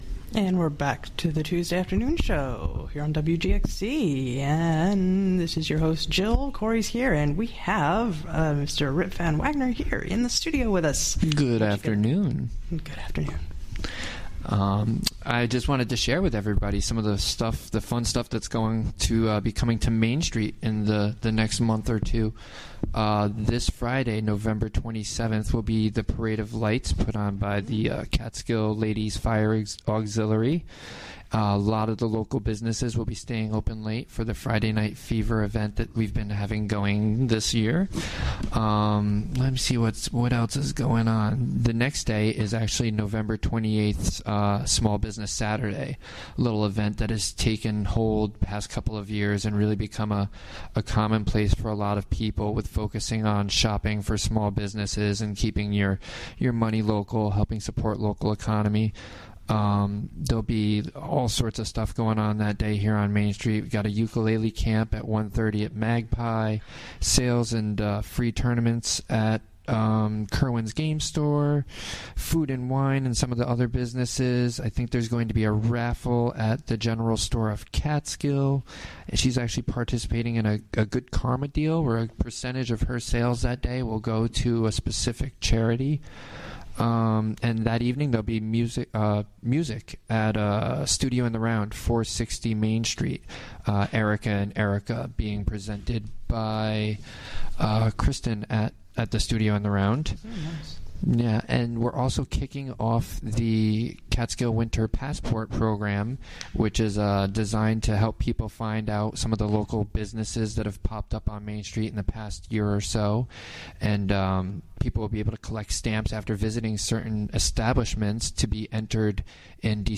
Recorded during the WGXC Afternoon Show, Tue., Nov. 17, 2015.